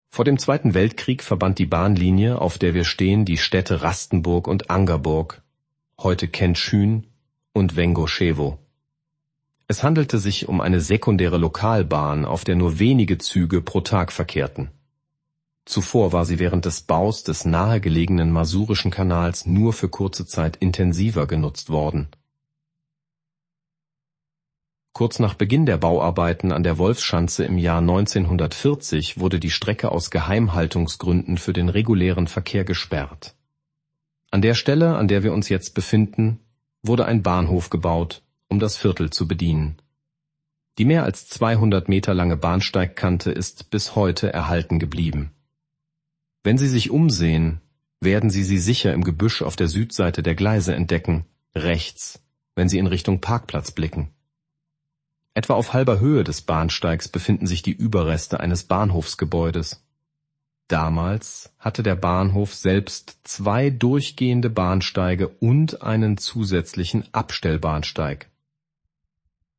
Audioguide zu Zone 2